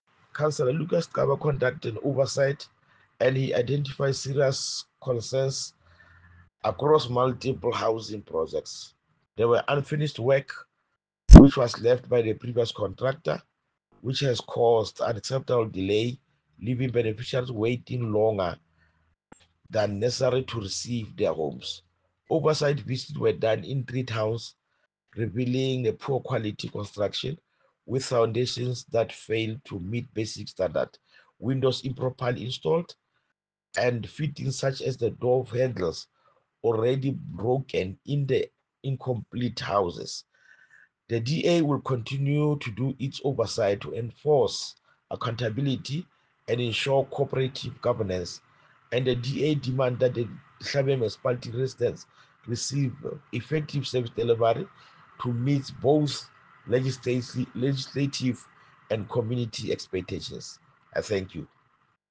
Sesotho soundbites by Cllr Lucas Xaba and